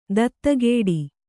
♪ dattagēḍi